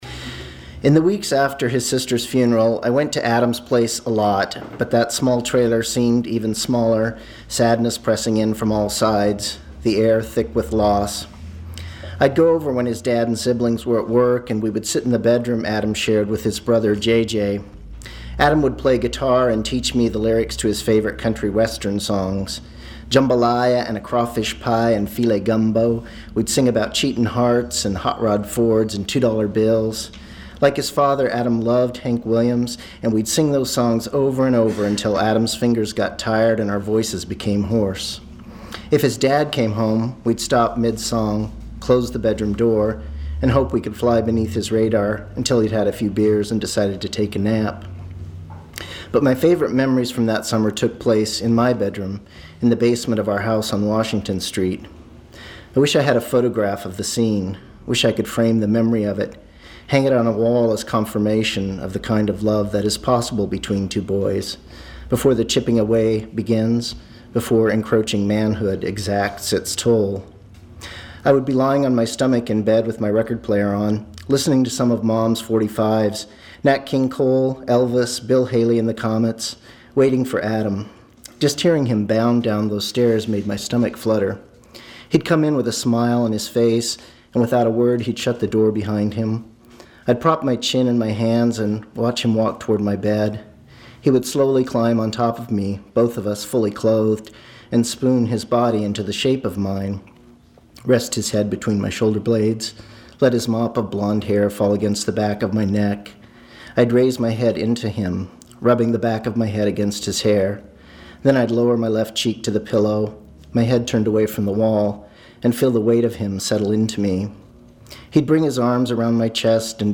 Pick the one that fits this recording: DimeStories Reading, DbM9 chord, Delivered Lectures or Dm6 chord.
DimeStories Reading